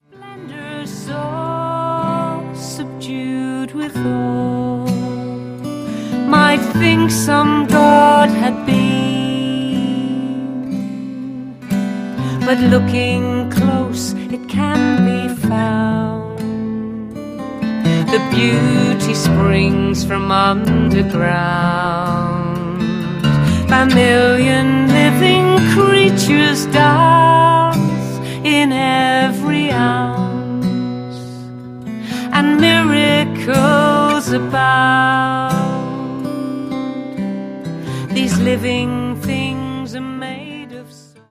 basically live